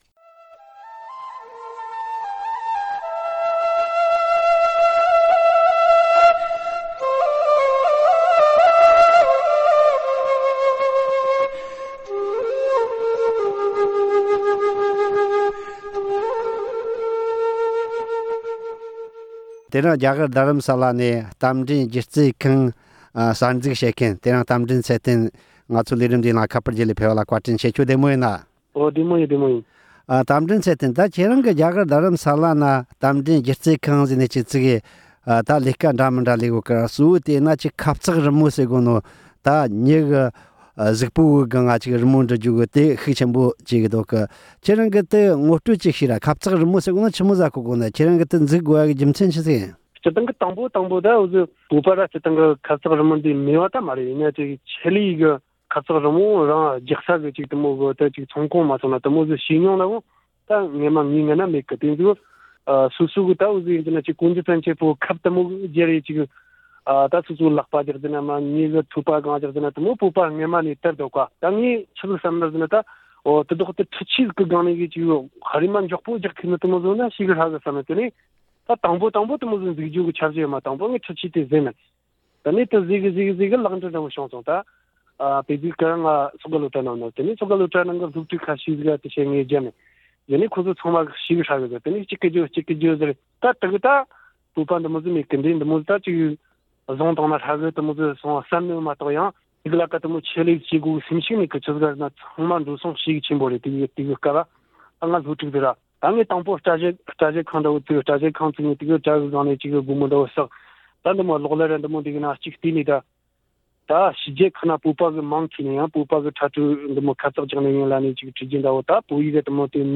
གླེང་མོལ་